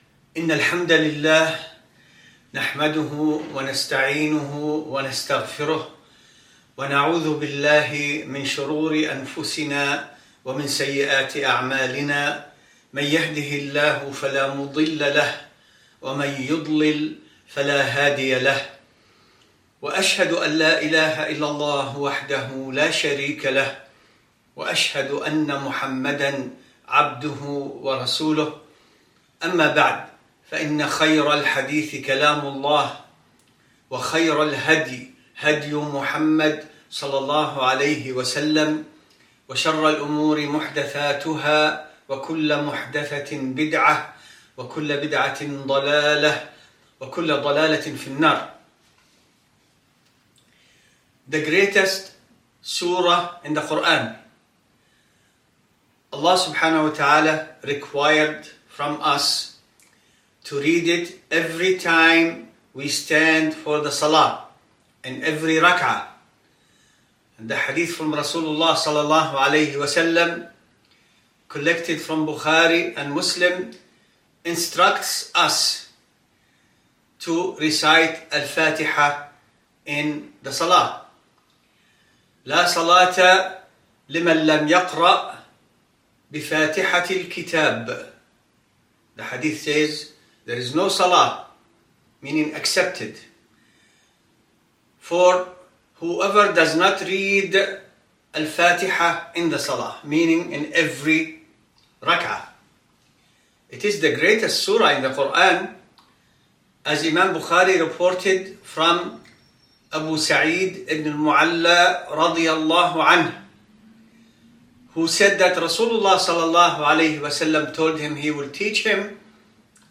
Friday Khutbah.mp3